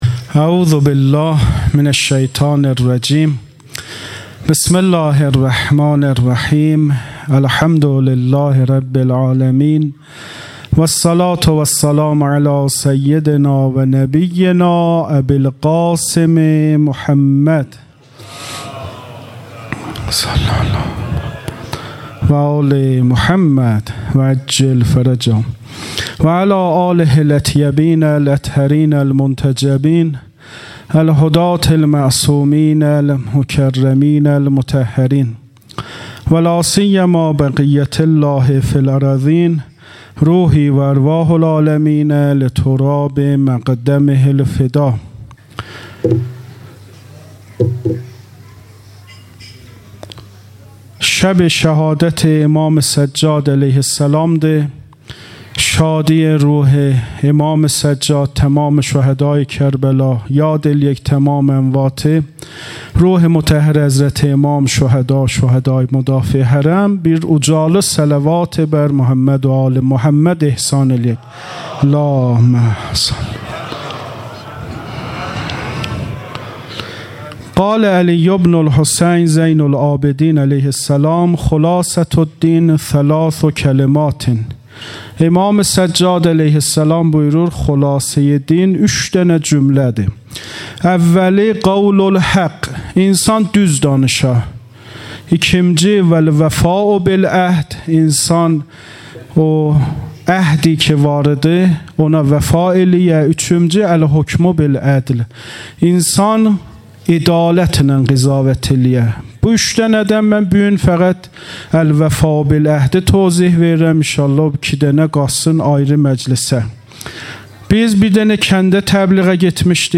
خیمه گاه - هیأت محبان اهل بیت علیهم السلام چایپاره - محرم 97 - شام غریبان- سخنرانی امام جمعه محترم